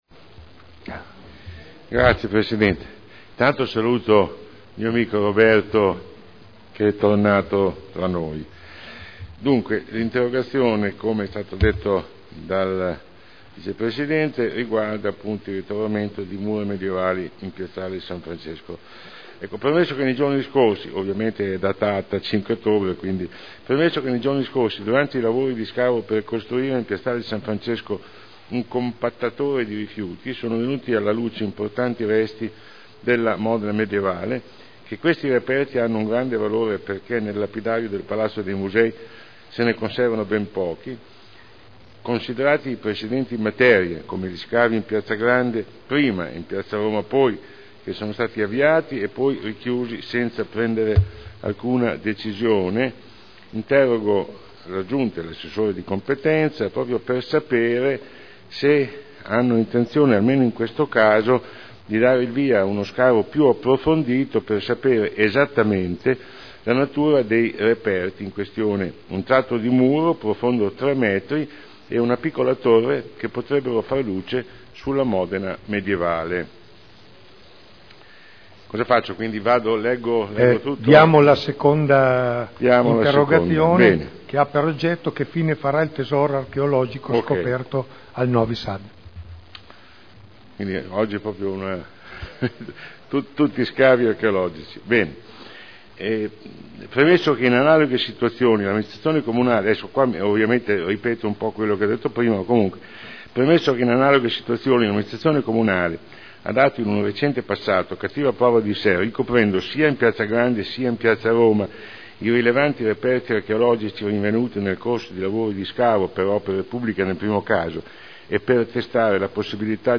Sandro Bellei — Sito Audio Consiglio Comunale
Seduta del 21/12/2009.